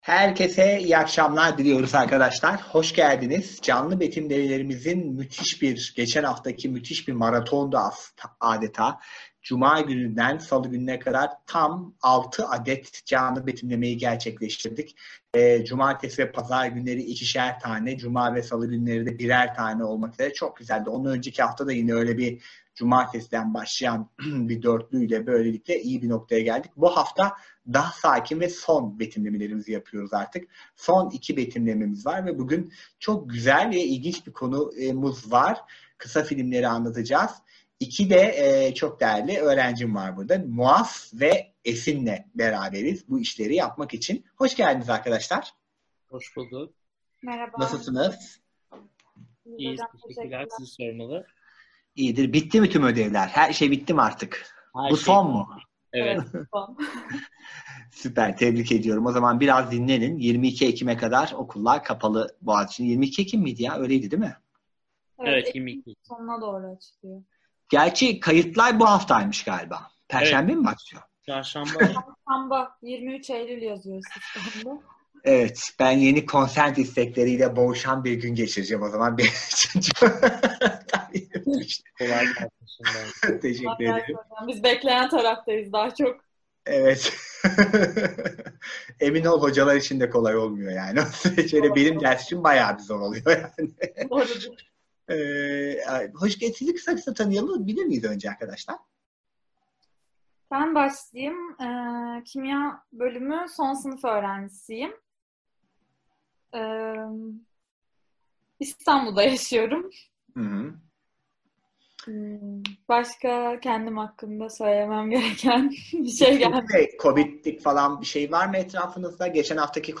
Canlı Betimlemeler